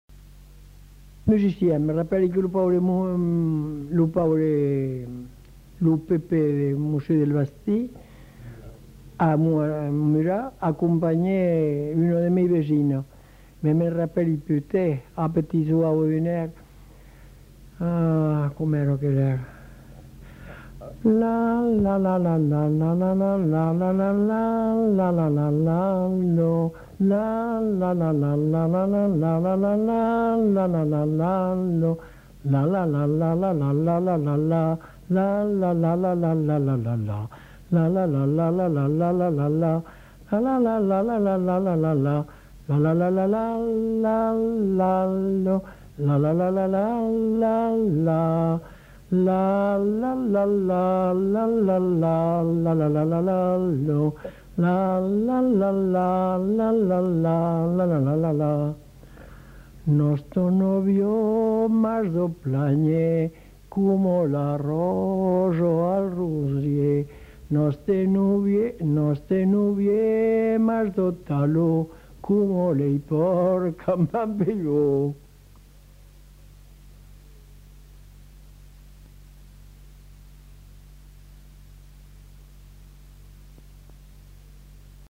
Aire culturelle : Haut-Agenais
Genre : chant
Effectif : 1
Type de voix : voix de femme
Production du son : fredonné
Danse : rondeau